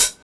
• Thin Pedal Hi-Hat Sample B Key 82.wav
Royality free pedal hi-hat sound tuned to the B note. Loudest frequency: 7679Hz
thin-pedal-hi-hat-sample-b-key-82-afq.wav